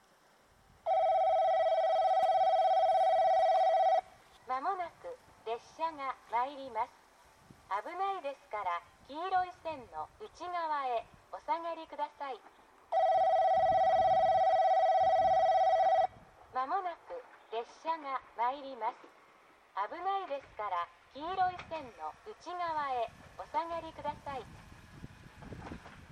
この駅では接近放送が設置されています。
２番のりば日豊本線
接近放送普通　南宮崎行き接近放送です。